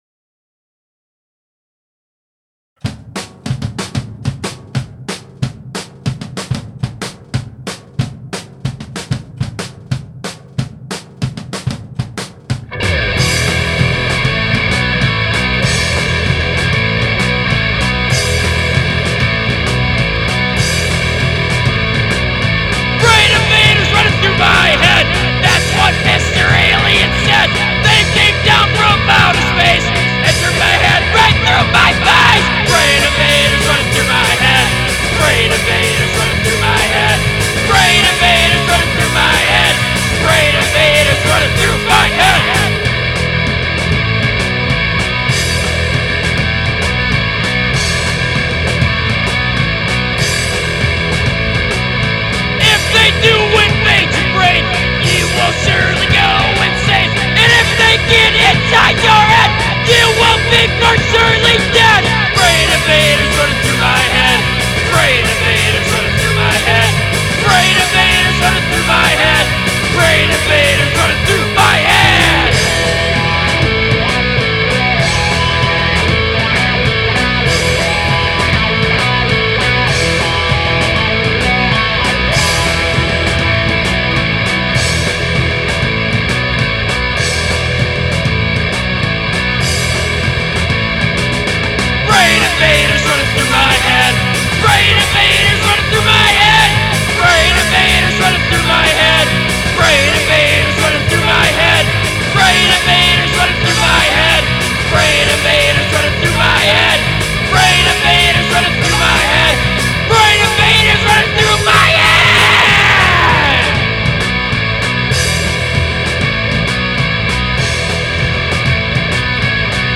a roughly-mixed version of